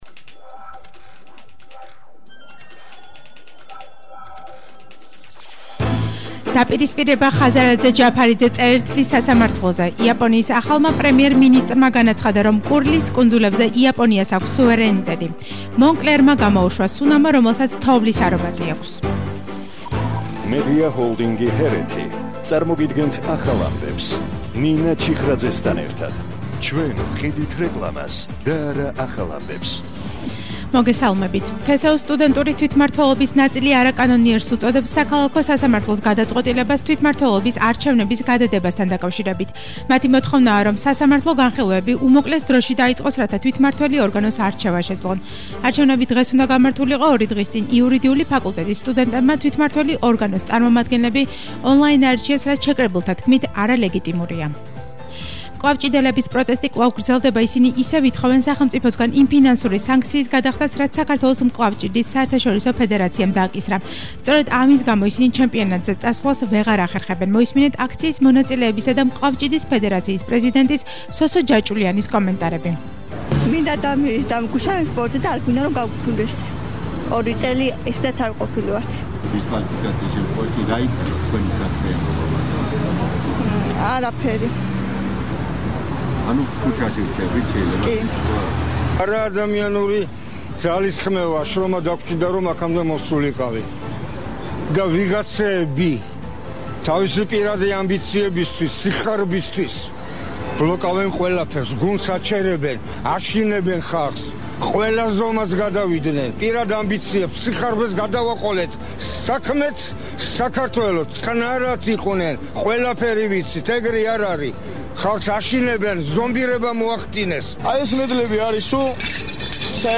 ახალი ამბები 17:00 საათზე –12/10/21 - HeretiFM